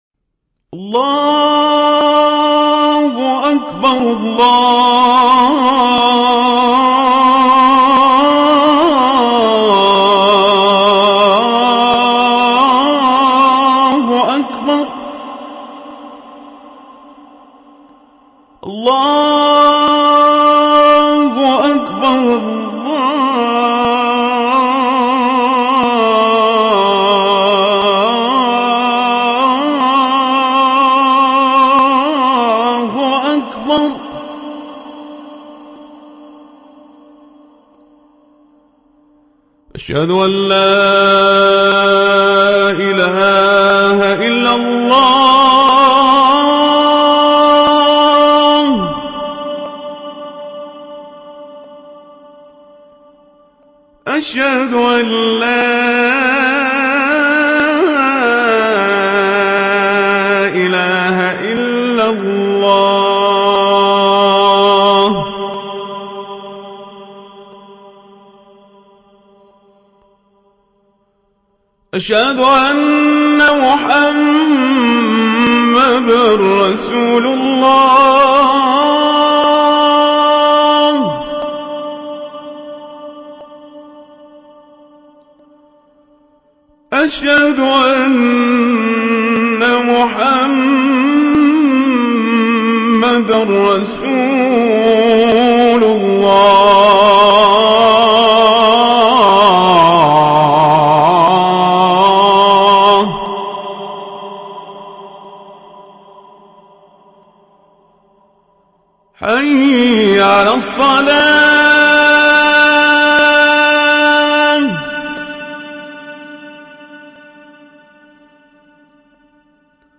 أذان القارئ الشيخ عمر القزابري